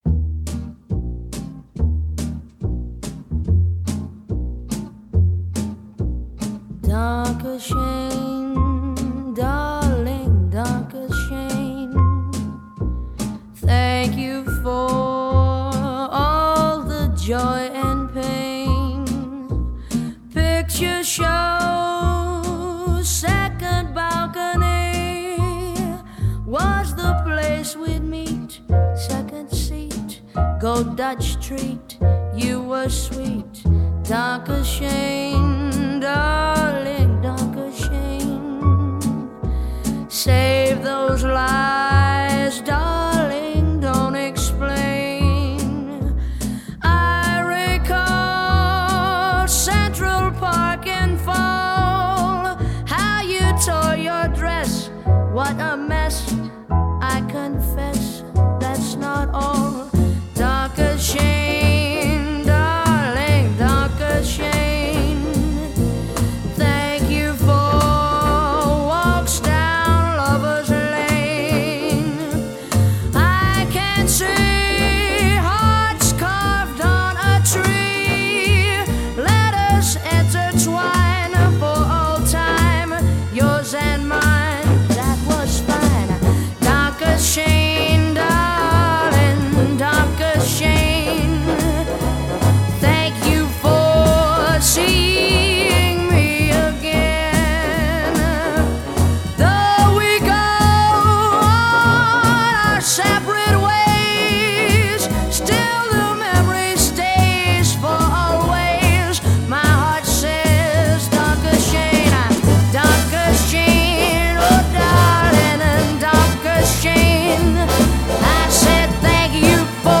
с красивым свингом